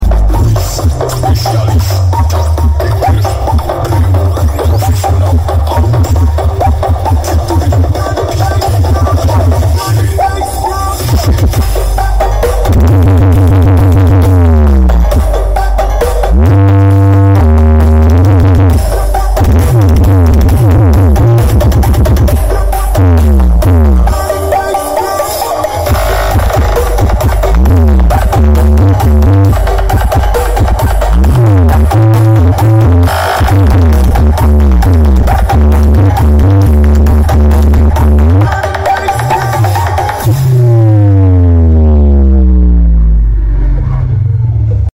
Brewog Audio.....karnaval wonokerto Bantur malang